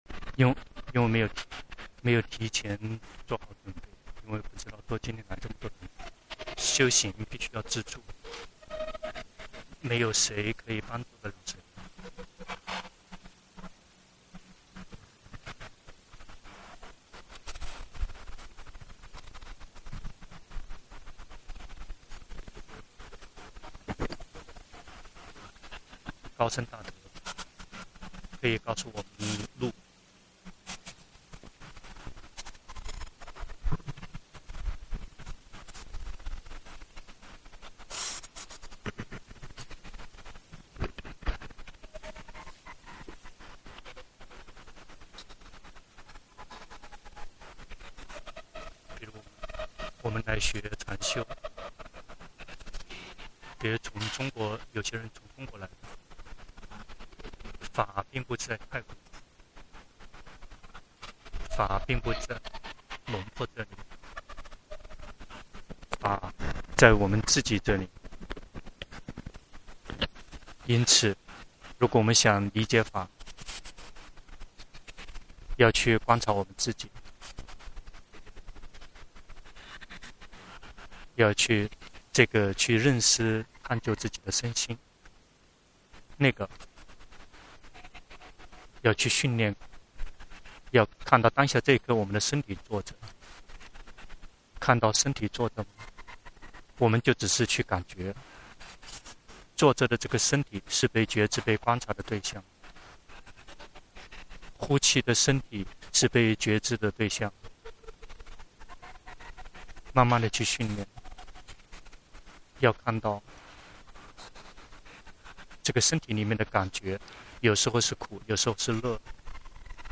長篇法談｜看到苦，才會放下